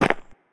multiple step sounds
step-3.ogg